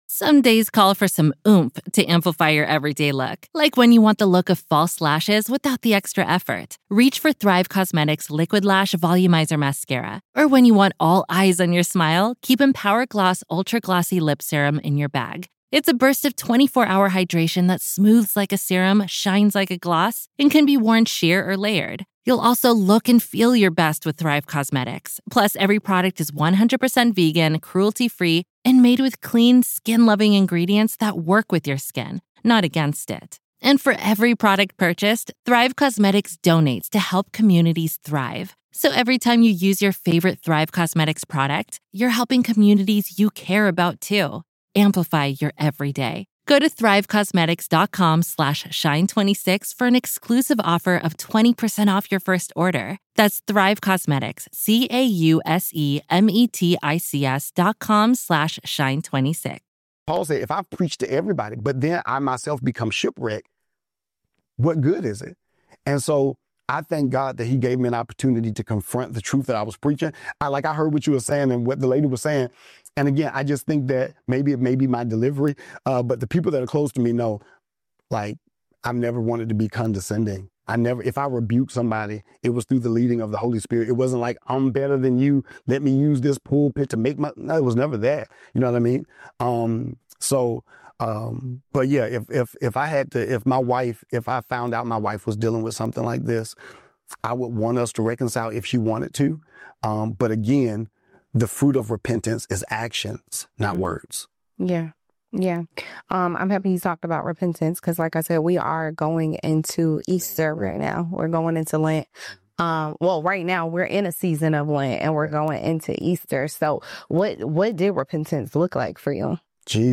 A pastor opens up about infidelity, being exposed, and what real repentance actually looks like. In this raw conversation, he breaks down confession, accountability, therapy, and the hard work of restoring a marriage and rebuilding trust.